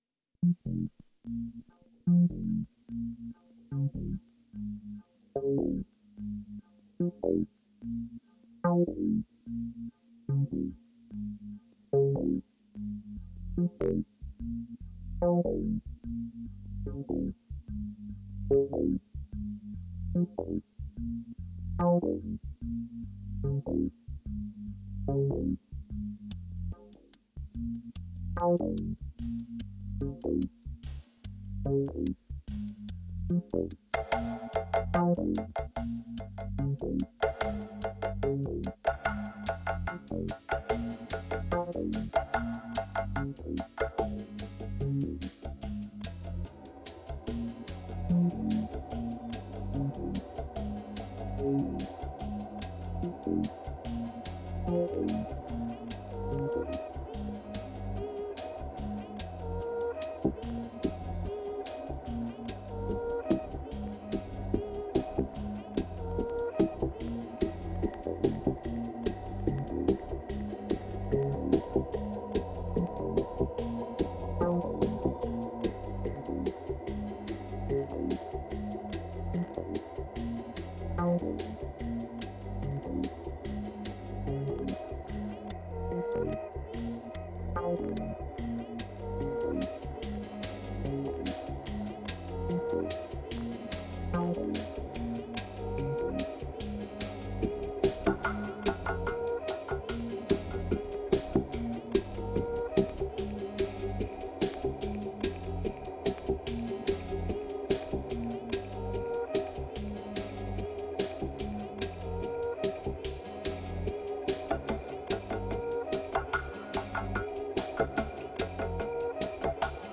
3025📈 - 28%🤔 - 73BPM🔊 - 2017-02-08📅 - -93🌟